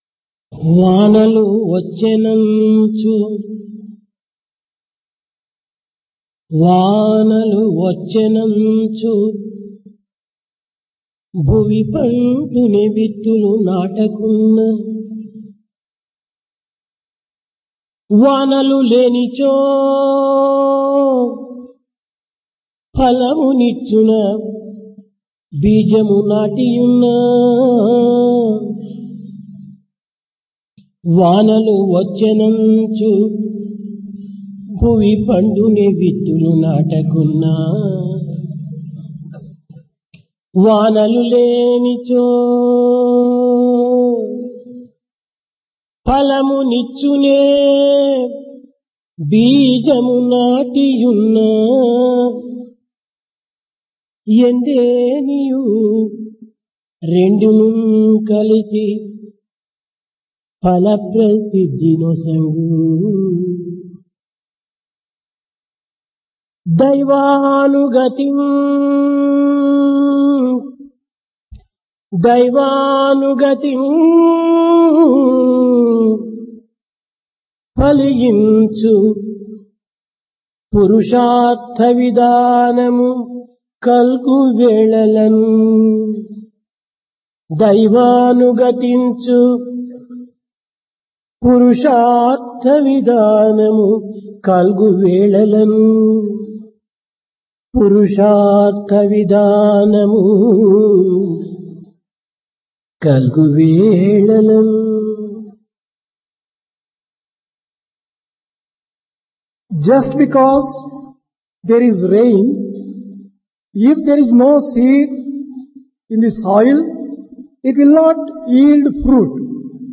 Discourse
Place Prasanthi Nilayam Occasion Shivarathri